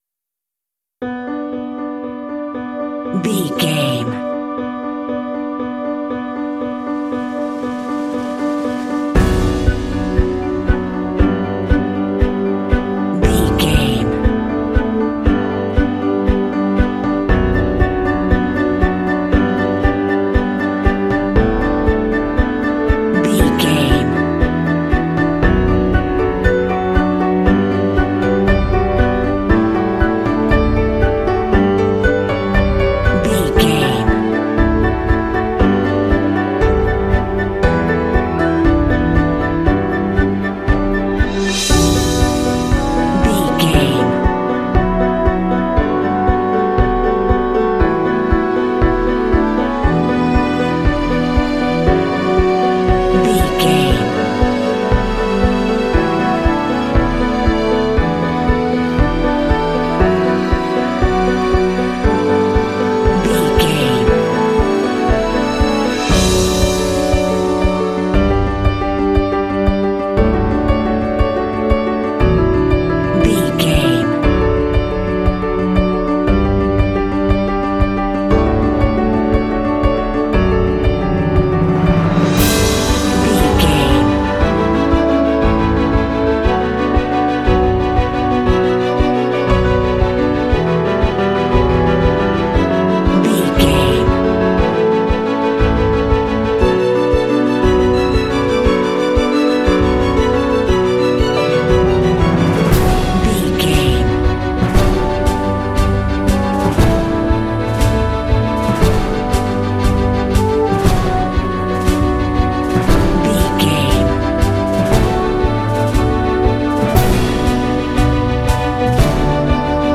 Epic / Action
Aeolian/Minor
tension
driving
dramatic
hopeful
piano
strings
orchestral
cinematic
contemporary underscore